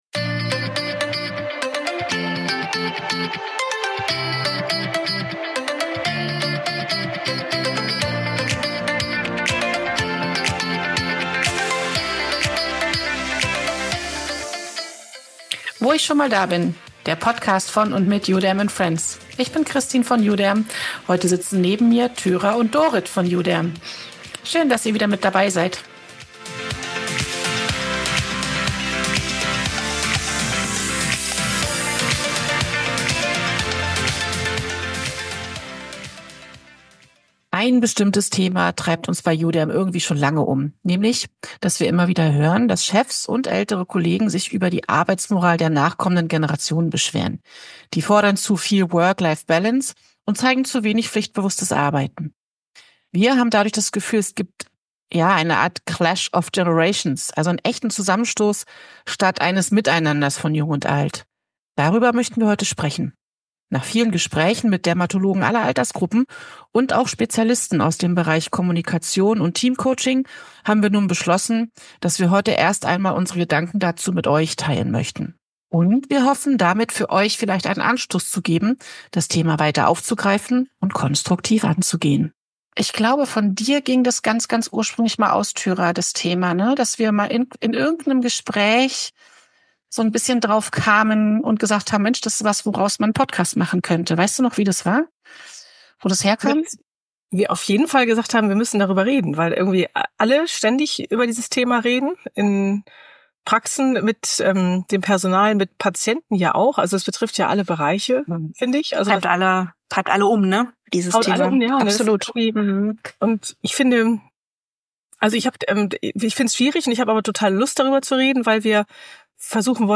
Wir diskutieren offen und ehrlich und teilweise kontrovers über unsere Wahrnehmungen und Frustrationen, aber auch über Hintergründe, Chancen und Lösungsansätze in der Zusammenarbeit.